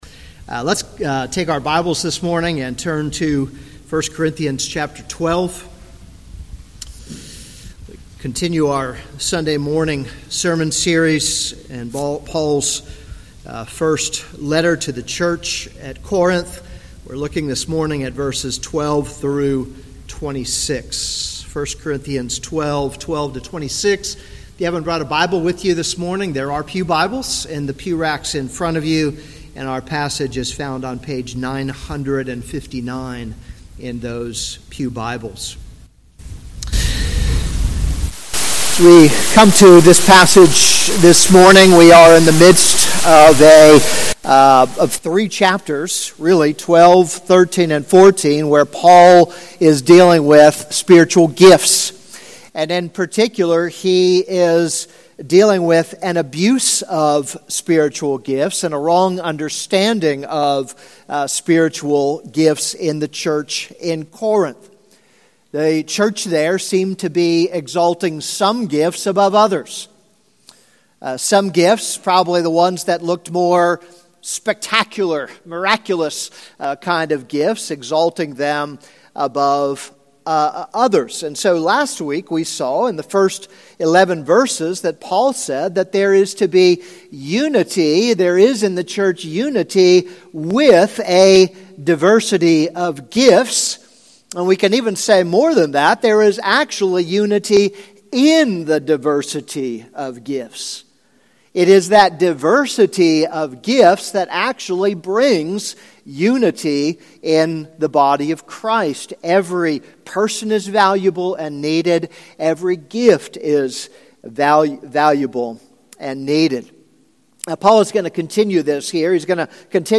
This is a sermon on 1 Corinthians 12:12-26.